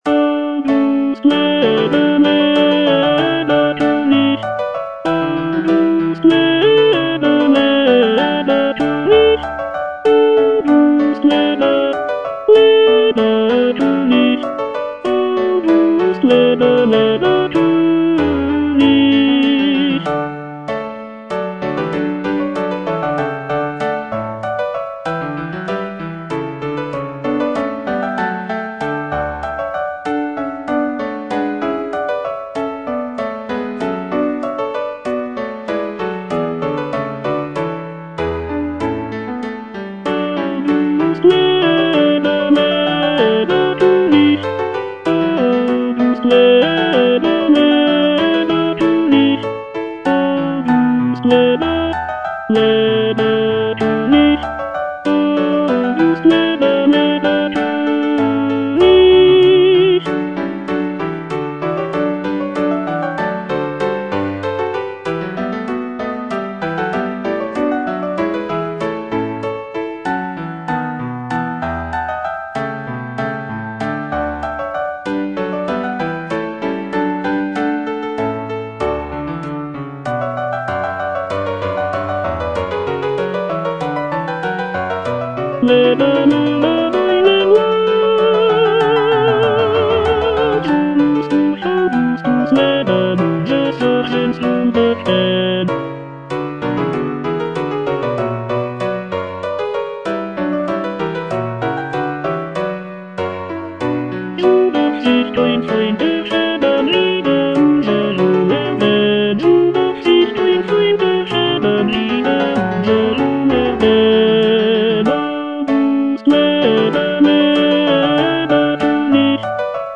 Choralplayer playing Cantata